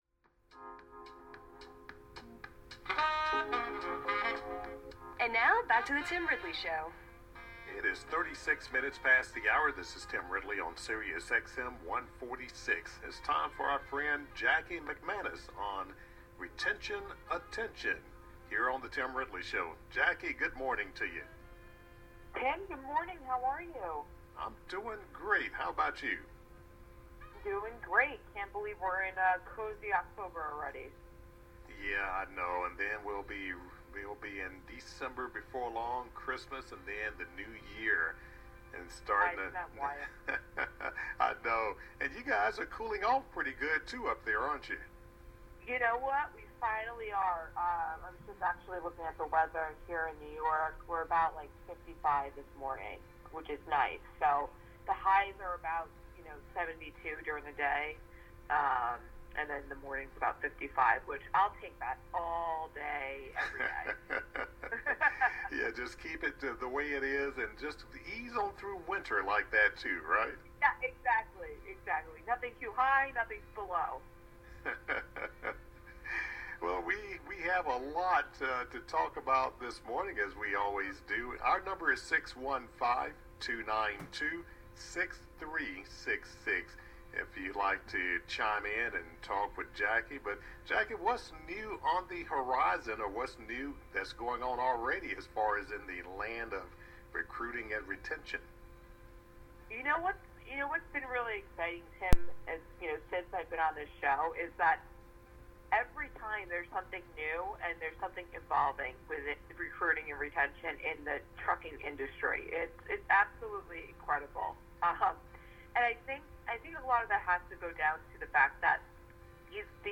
One driver calls in and another emails, both confirming the importance flexibility in the workplace.